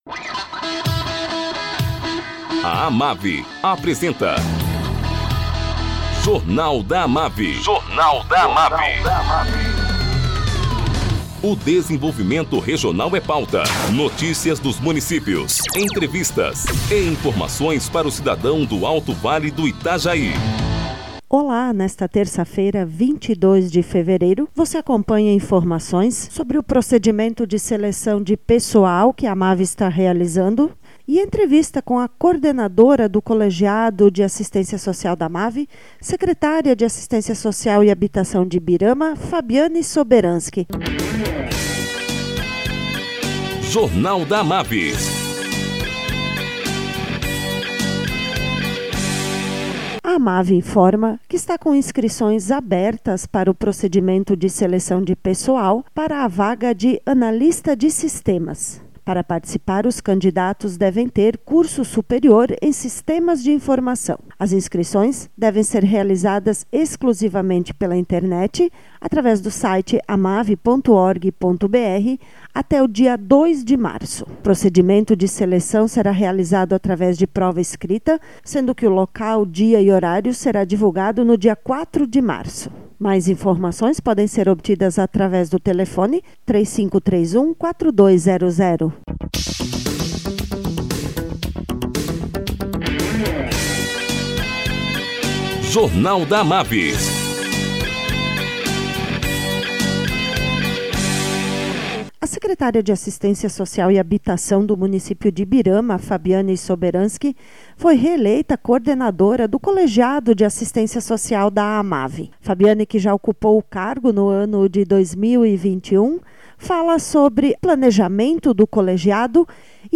Entrevista com a secretária de Assistência Social e Habitação de Ibirama e coordenadora do Colegiado de Assisitência Social da AMAVI, Fabiani Soberanski.